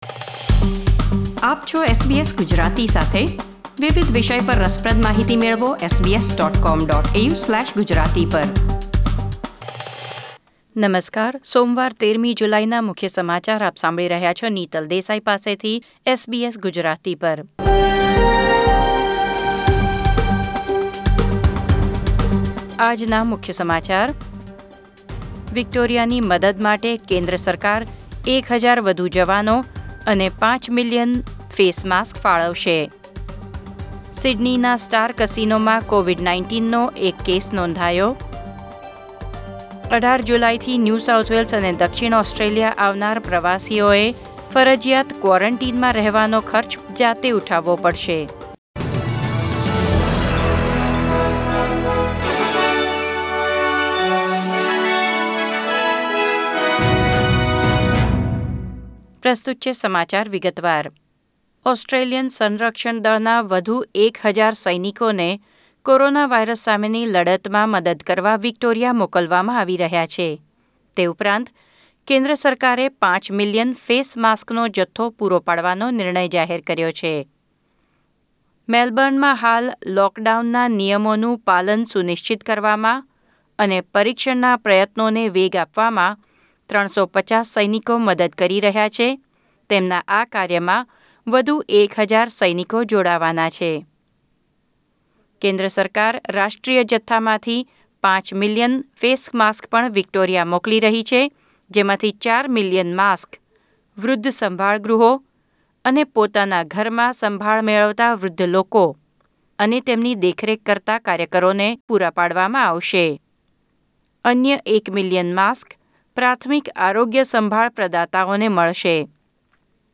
SBS Gujarati News Bulletin 13 July 2020